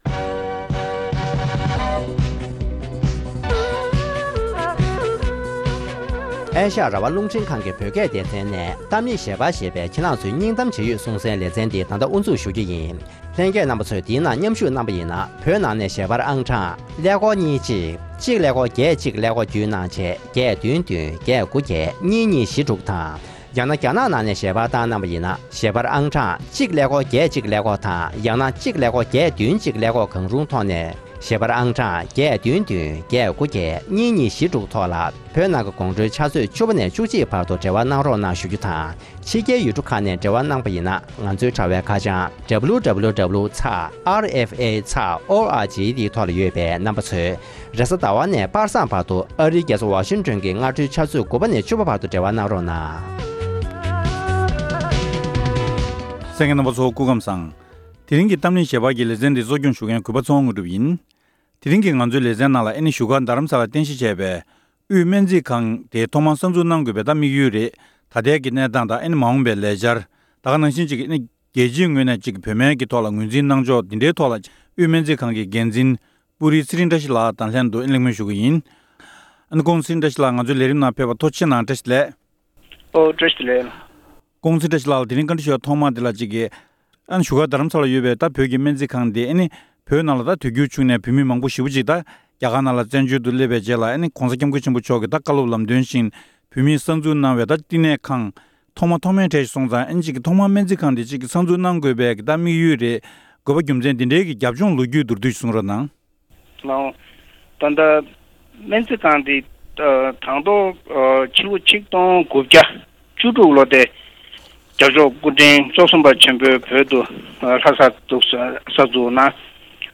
༄༅། །དེ་རིང་གི་གཏམ་གླེང་གྱི་ལེ་ཚན་ནང་།